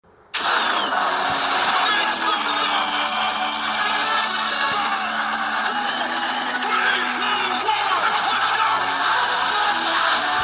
Ktoś zna kawałek intro z sunrise festival 2024 dzień III - Muzyka elektroniczna
Ktoś zna kawałek intro z sunrise festival 2024 dzień III